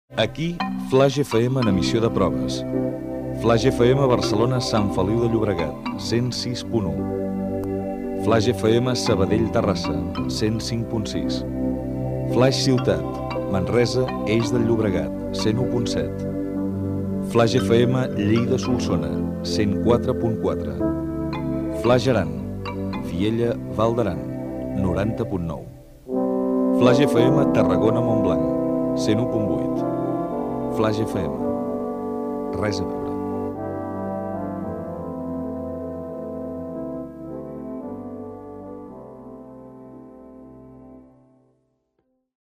Identificació de l'emissió en proves, freqüències i localitats d'emissió.
Emissió en proves.